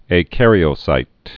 (ā-kărē-ō-sīt)